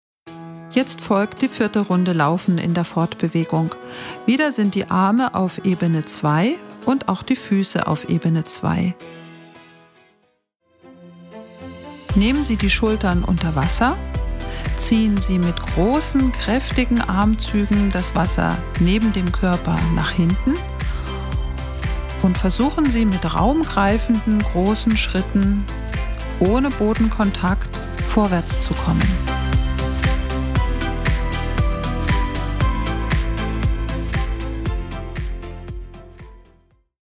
• Motivation durch Musik & professionell gesprochene Anleitungen
Hör doch mal rein: Hier bekommst du einen ersten Eindruck, wie dich Stimme und Musik durch dein Wasser-Workout begleiten.